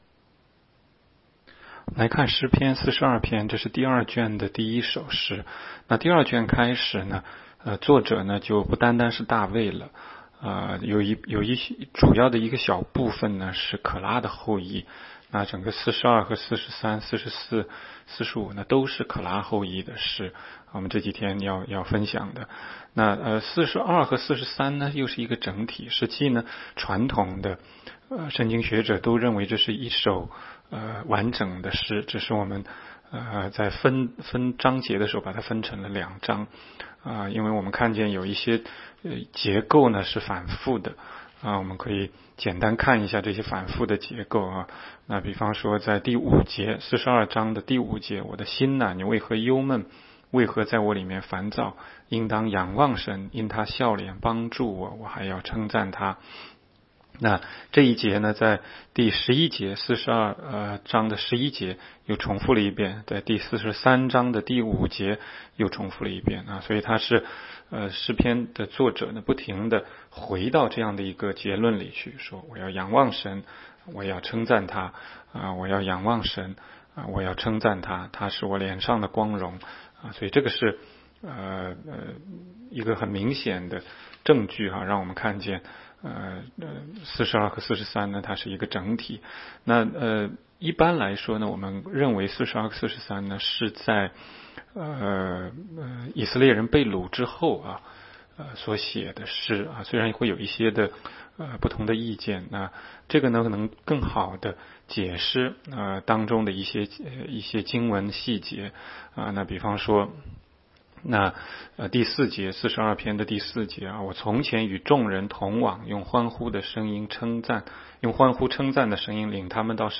16街讲道录音 - 每日读经-《诗篇》42章